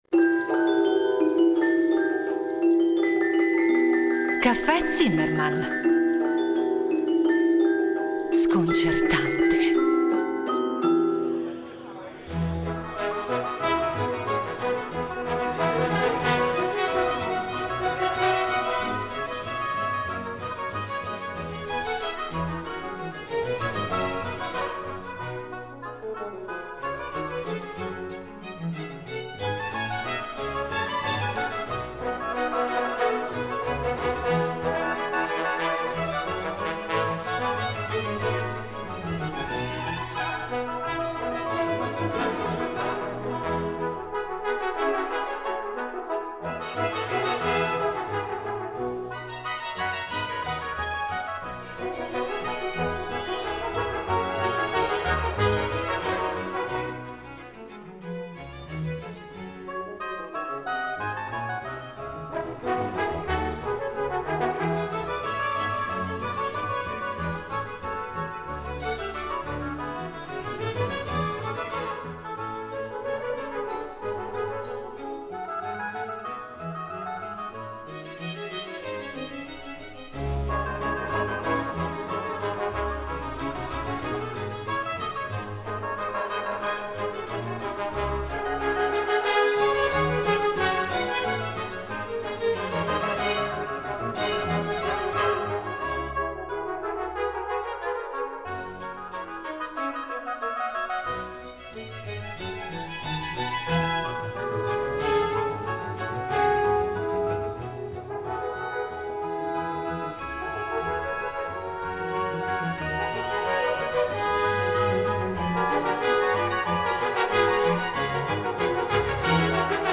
Ensemble che, a geometria variabile, in base alle esigenze di ogni programma, conta da sei fino ad oltre una trentina di musicisti. A dirigere Il Giardino Armonico dal 1989 Giovanni Antonini , tra i membri fondatori, che ai microfoni di Rete Due ne ripercorre la storia.
A guidarci le musiche della rilevante quanto varia discografia dell’ensemble (proposta in ordine cronologico).